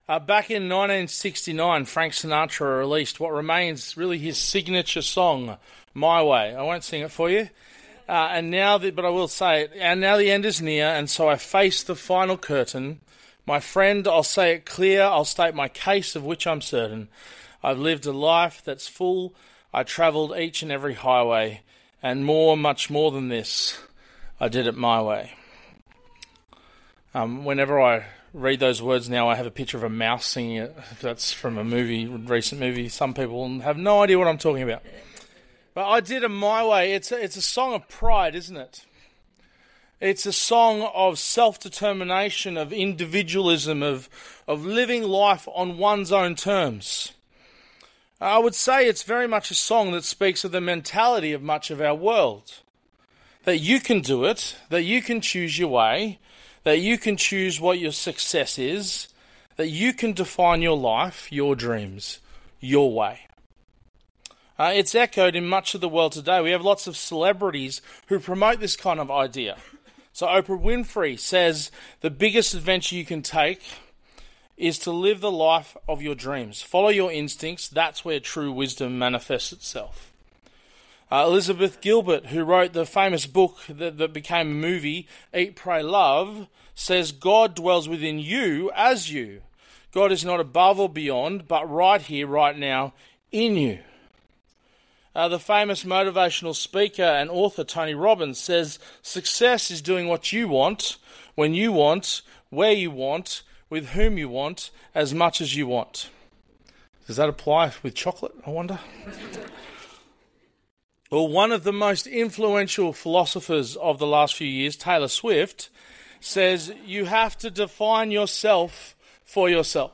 2025 Current Bible Talk Sin continued...